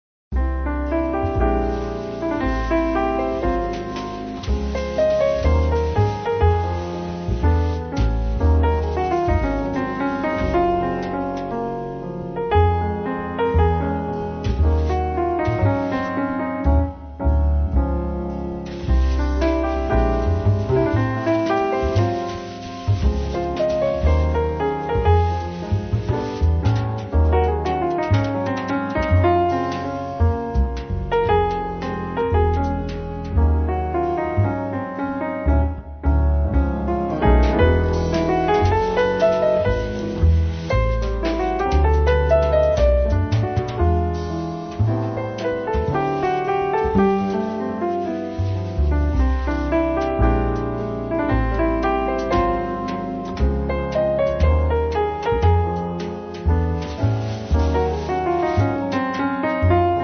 pianoforte
basso
batteria